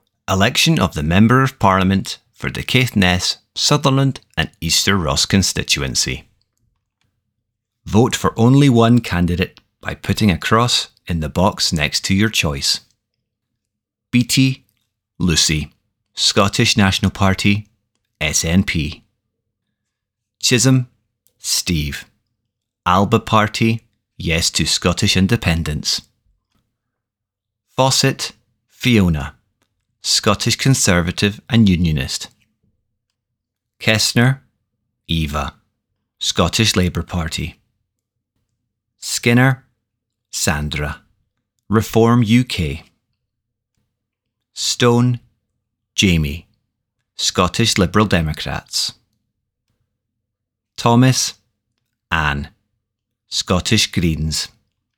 UK Parliamentary General Election 2024 - Spoken Ballot Papers Caithness, Sutherland and Easter Ross Ballot Paper
caithness_sutherland_and_easter_ross_ballot_paper.mp3